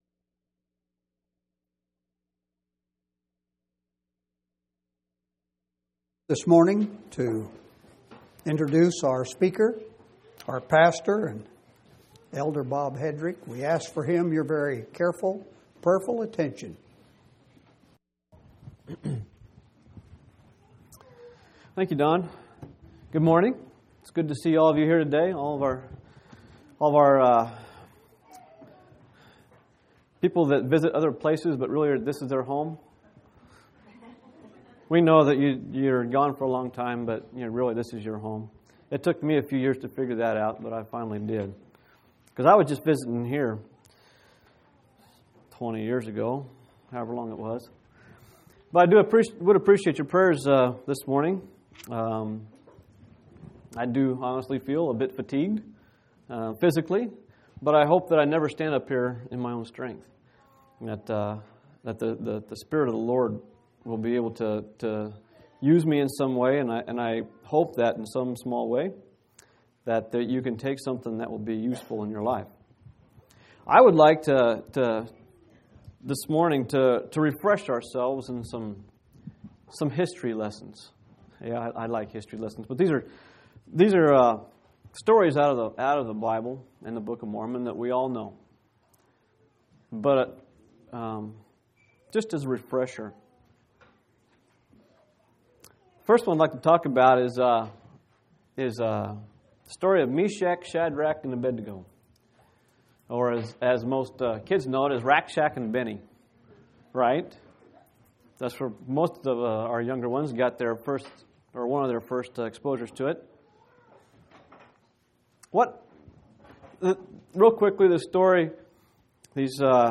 11/20/2005 Location: Phoenix Local Event